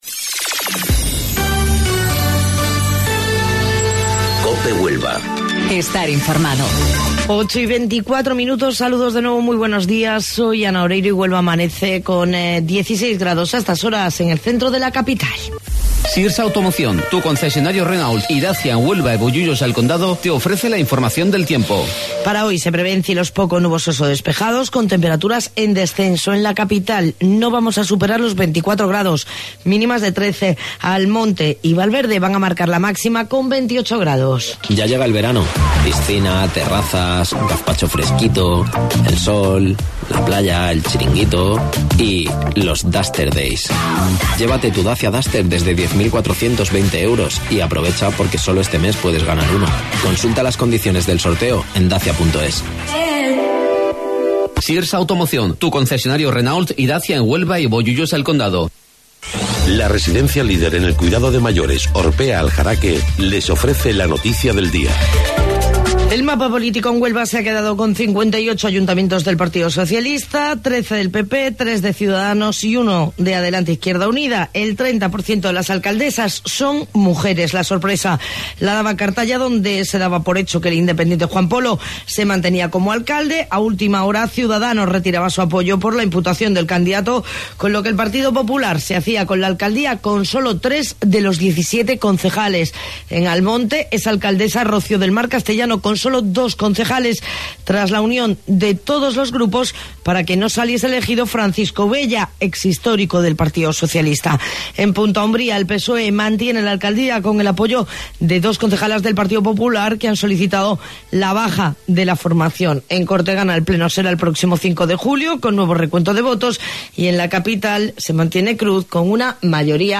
AUDIO: Informativo Local 08:25 del 17 de Junio